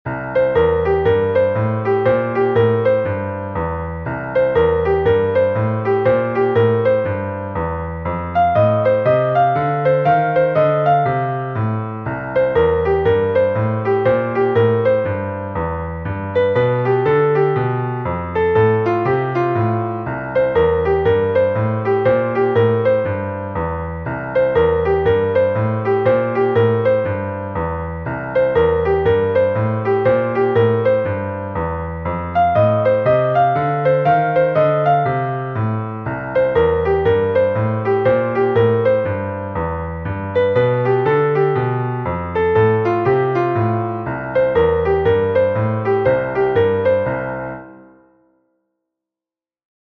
Blues zu Fuß (Akkordeon-Blues)
Also: //: C - E - G - A / Bb - A - G - E : // //: F - A - C - D / Eb - D - C - A : // //: G - H - D - E / F - E - D - H : // Dann kann man sich einem einfachen Blues mit Walking Bass nähern.